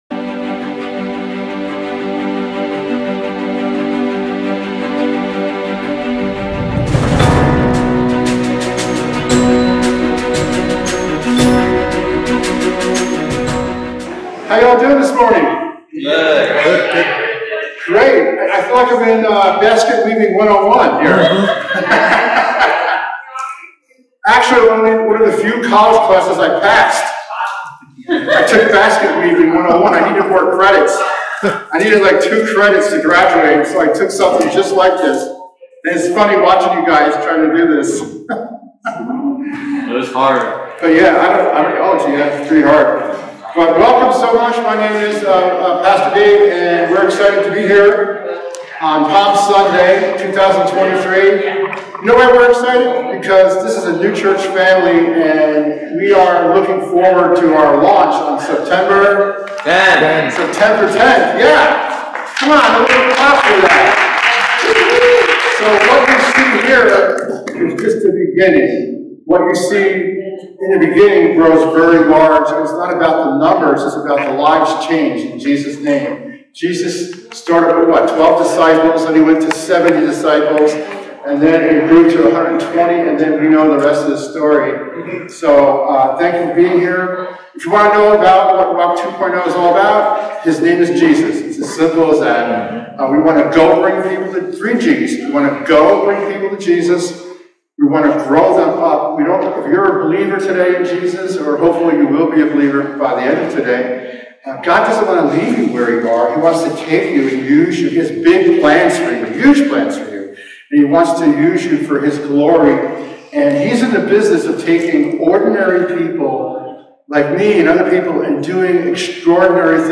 2023 Rejection-Palm Sunday Preacher